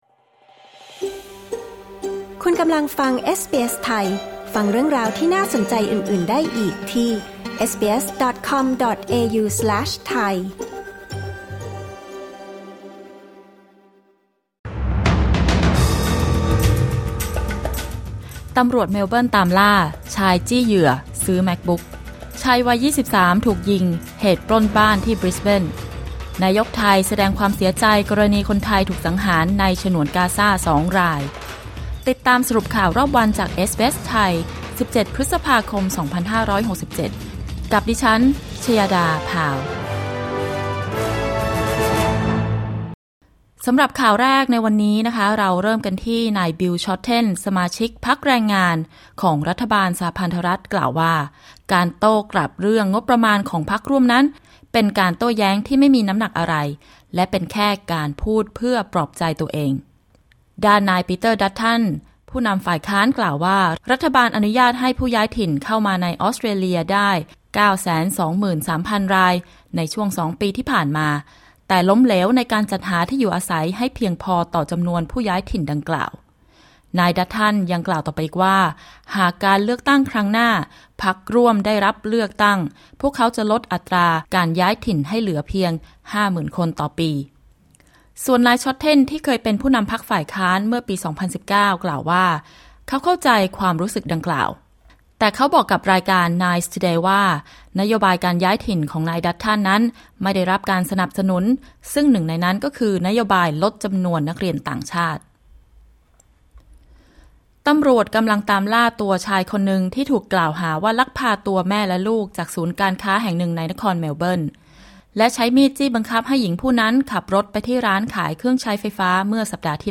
สรุปข่าวรอบวัน 17 พฤษภาคม 2567